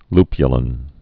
(lpyə-lən)